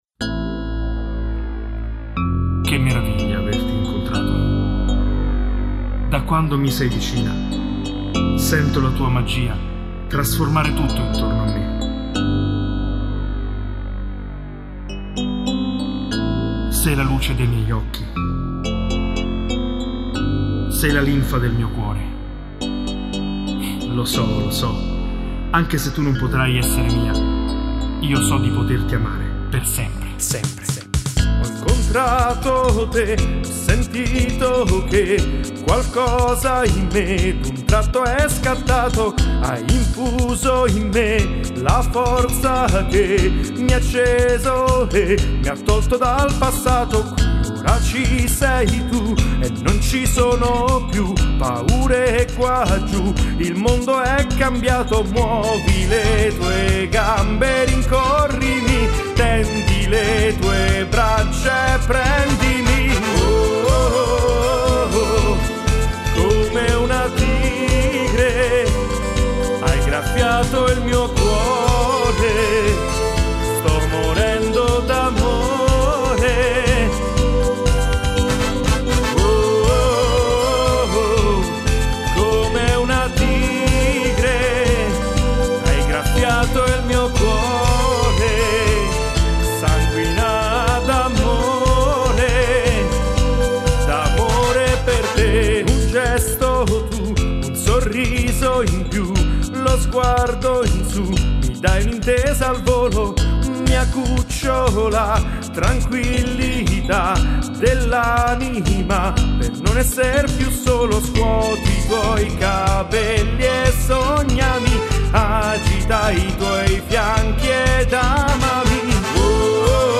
Voci, Piano, Synthesizers, Batteria Elettronica